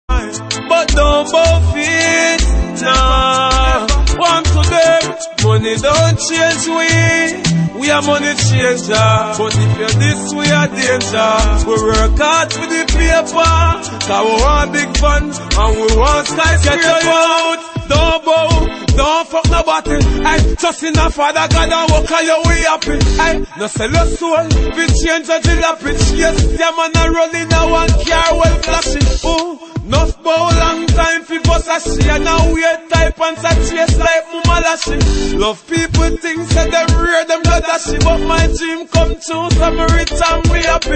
• Reggae Ringtones